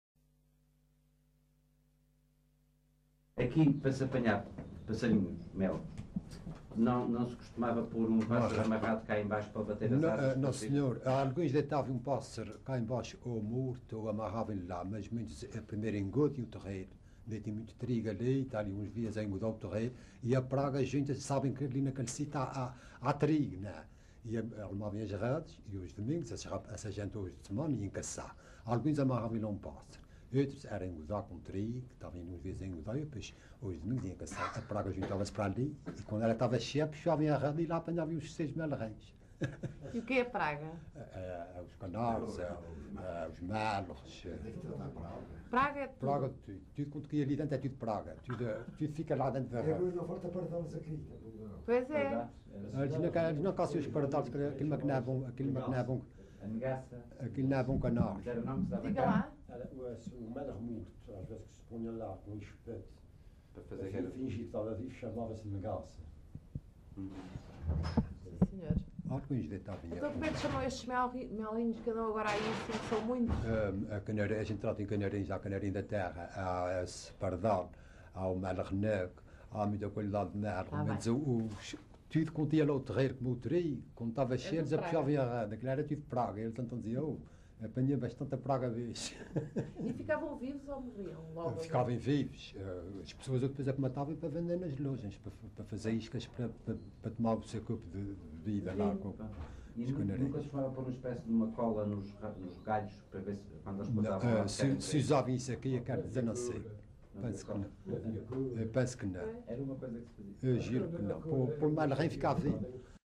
LocalidadePonta Garça (Vila Franca do Campo, Ponta Delgada)